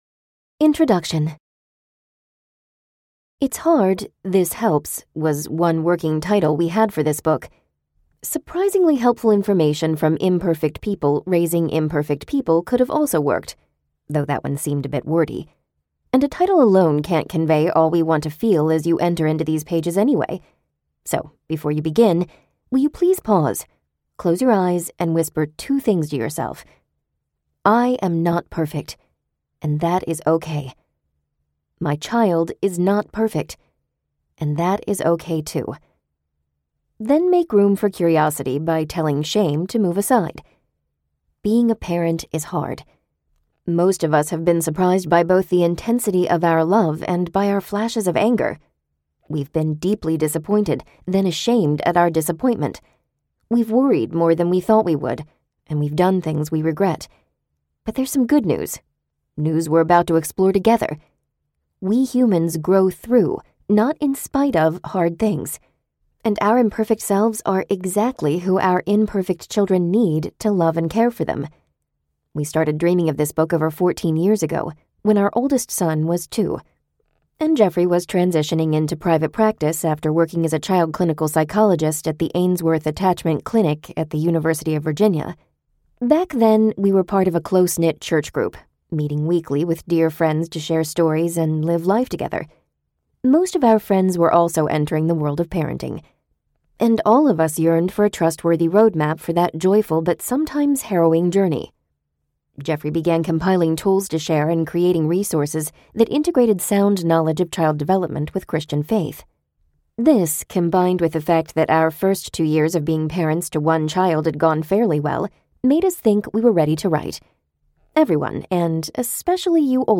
The 6 Needs of Every Child Audiobook
Narrator
6.1 Hrs. – Unabridged